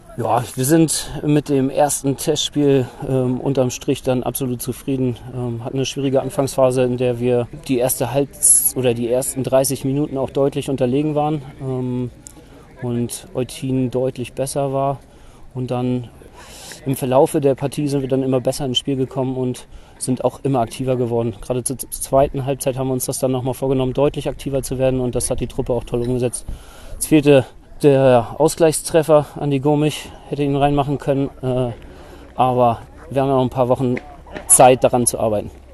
Stimmen